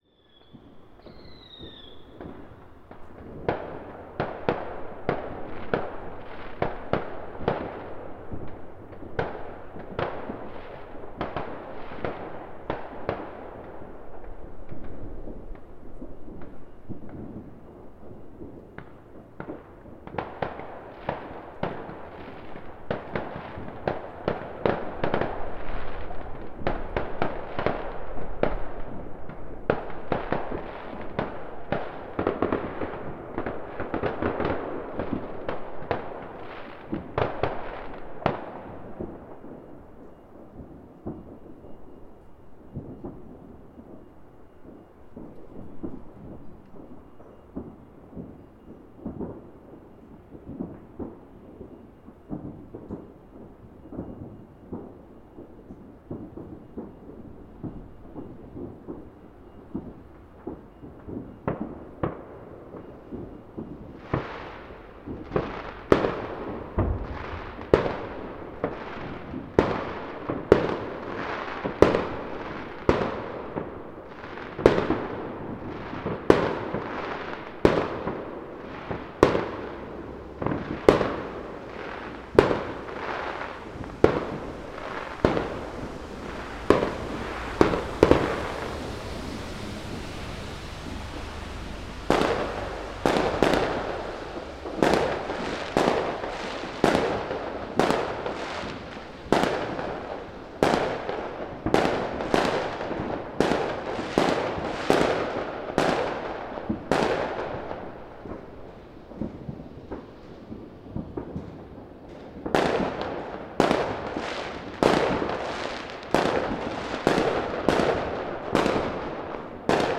Every new year, I set up some microphones to capture the fireworks. This is last night in Tallinn.